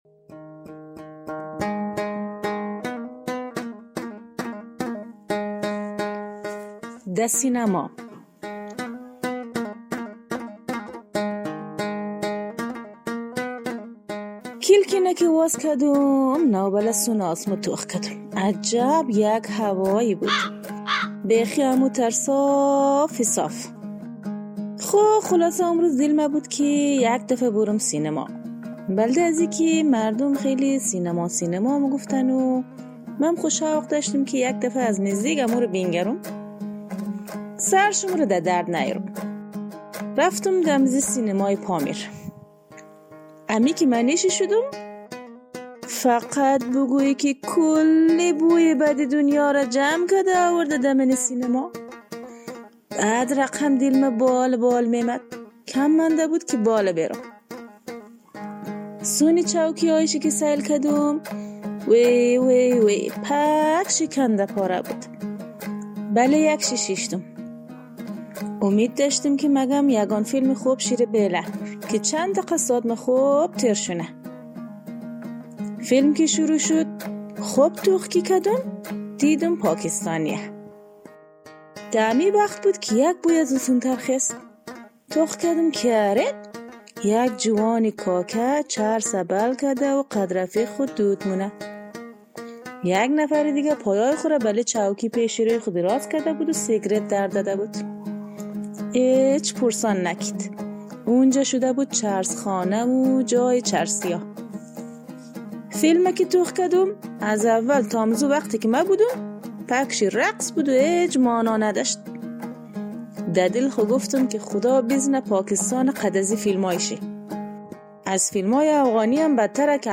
در برنامه طنز «توخ کو» با لهجه شیرین هزارگی ، به مسائل فرهنگی افغانستان می پردازیم.